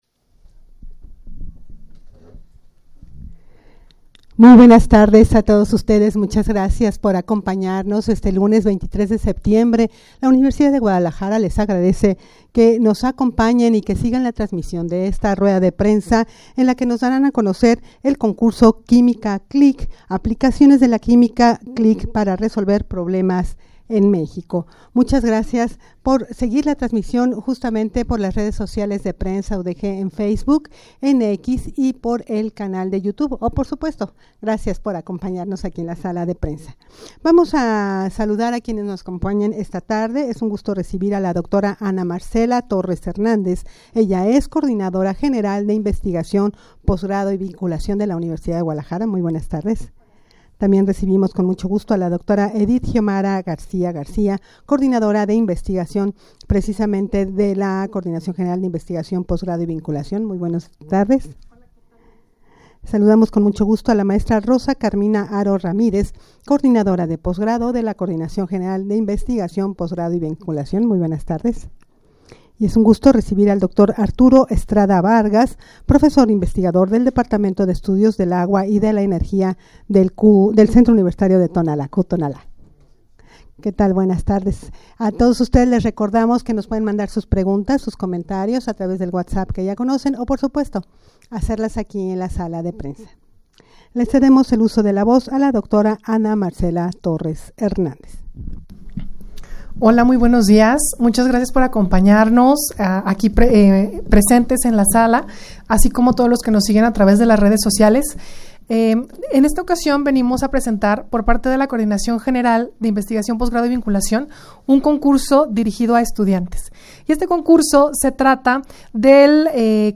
Audio de la Rueda de Prensa
rueda-de-prensa-para-dar-a-conocer-el-programa-quimica-click.mp3